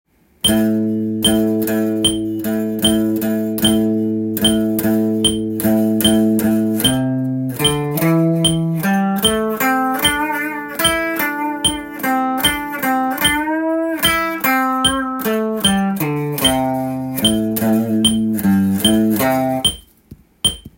スケールでリズム練習tab
⑤のリズムは２拍目で裏のリズムになりますので